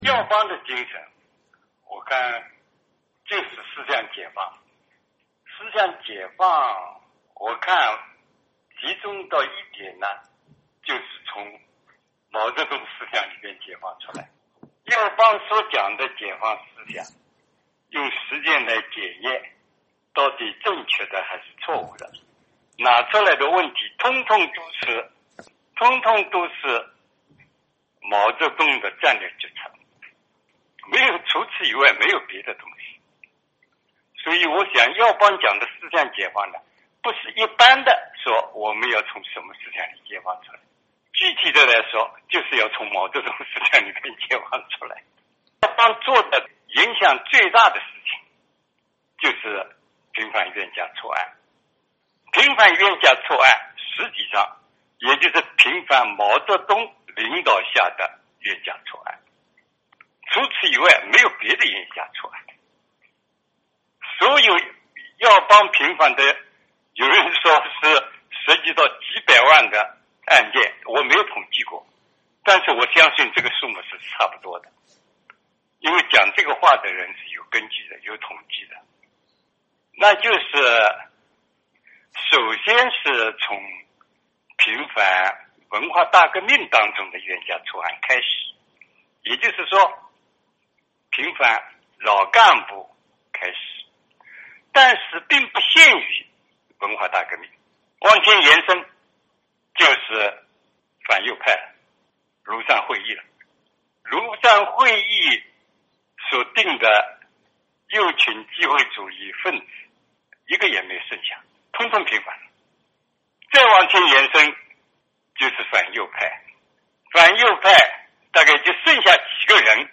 （根据电话采访录音整理，受访者观点不代表美国之音）